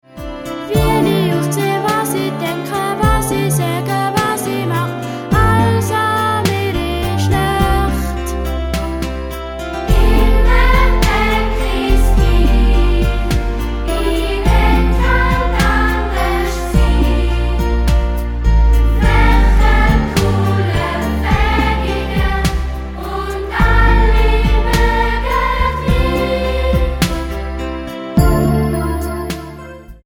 Musical - CD mit Download-Code